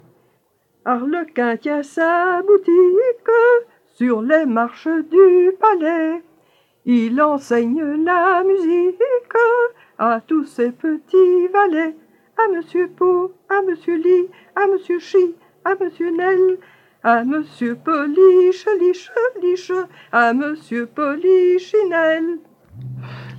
Genre : chant
Type : chanson d'enfants
Interprète(s) : Anonyme (femme)
Support : bande magnétique